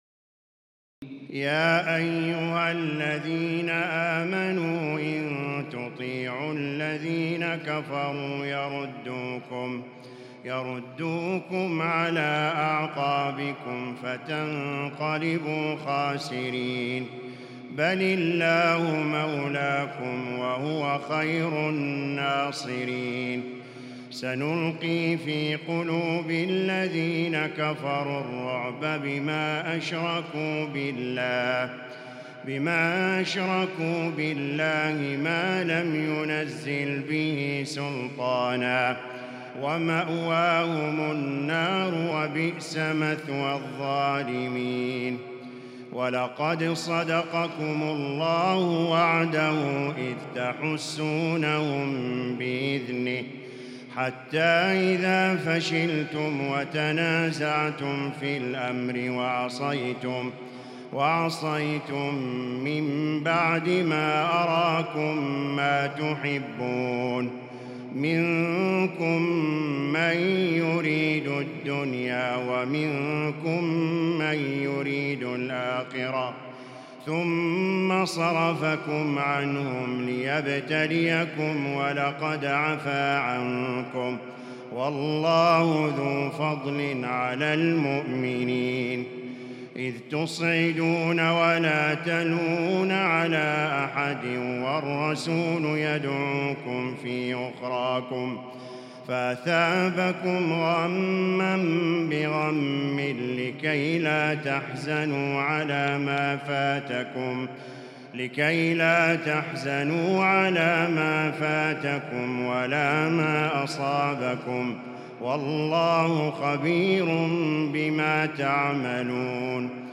تراويح الليلة الرابعة رمضان 1438هـ من سورتي آل عمران (149-200) و النساء (1-22) Taraweeh 4 st night Ramadan 1438H from Surah Aal-i-Imraan and An-Nisaa > تراويح الحرم المكي عام 1438 🕋 > التراويح - تلاوات الحرمين